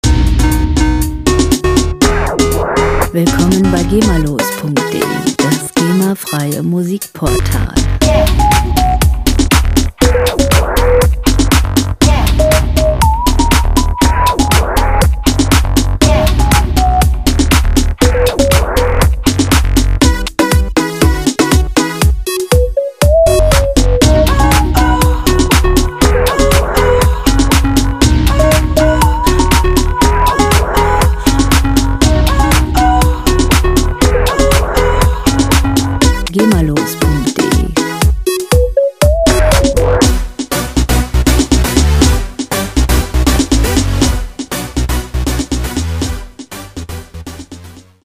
• Electro Pop